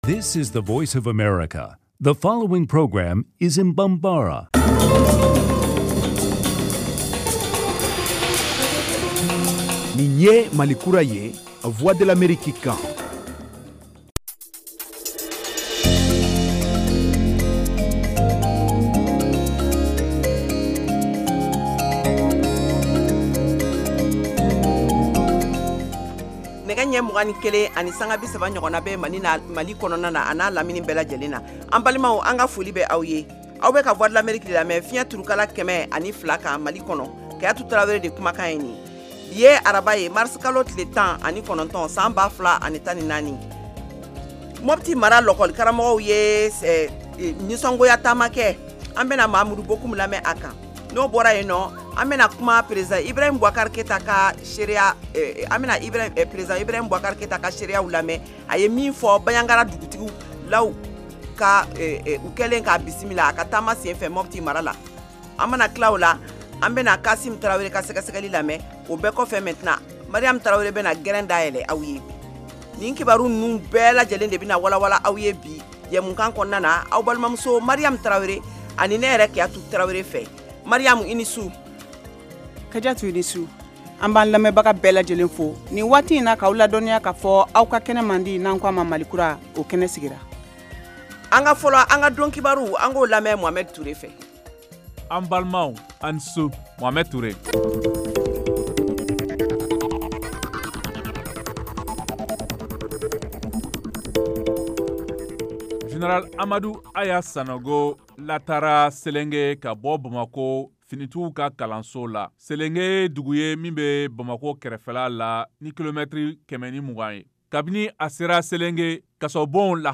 Emission quotidienne
en direct de Washington. Au menu : les nouvelles du Mali, les analyses, le sport et de l’humour.